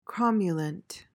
PRONUNCIATION:
(KROM-yuh-luhnt)